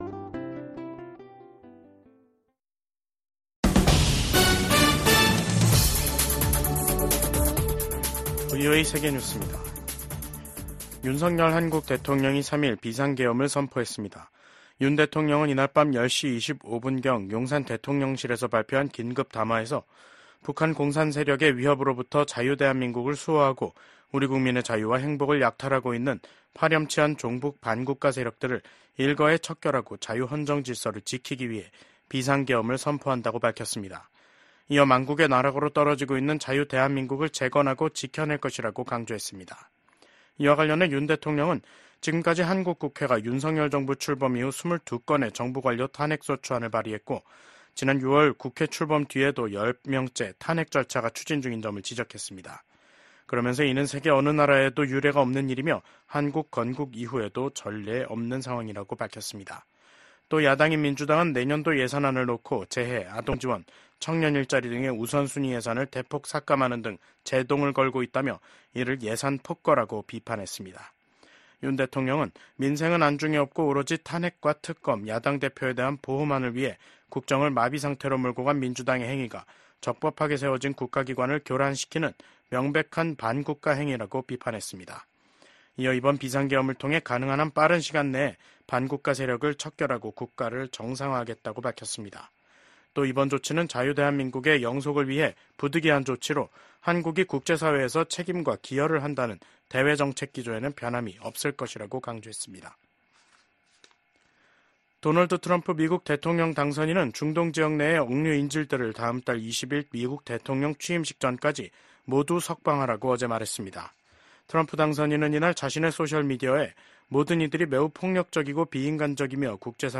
VOA 한국어 간판 뉴스 프로그램 '뉴스 투데이', 2024년 12월 3일 3부 방송입니다. 미국 국무부는 북러 간 군사협력을 매우 우려한다며 북한군 파병 등에 대응해 우크라이나 방위력 강화를 위한 조치를 취할 것이라고 밝혔습니다. 러시아에 파병된 북한군이 아직 최전선에서 공격 작전에 참여하지 않고 있다고 미국 국방부가 밝혔습니다. 북한이 연말에 노동당 중앙위원회 전원회의를 열어 한 해를 결산합니다.